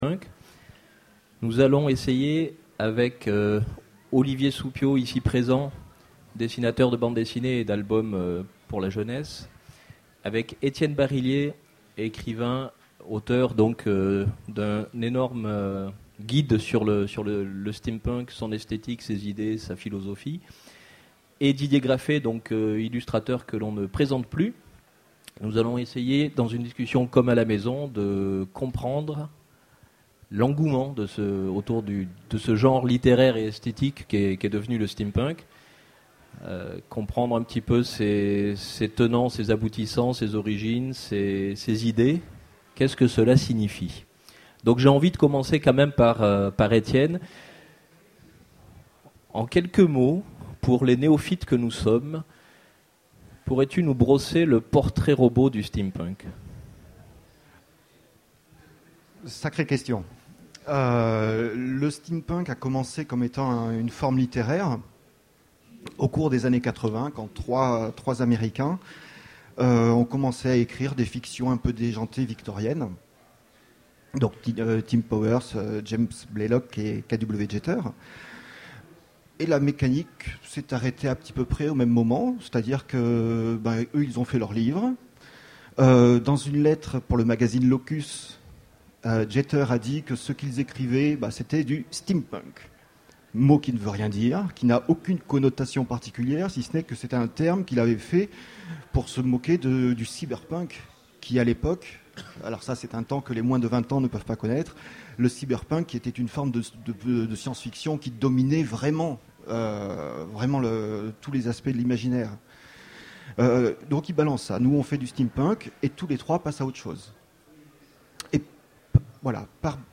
Utopiales 2011 : Conférence Le succès du steampunk est-il essentiellement basé sur son esthétique ?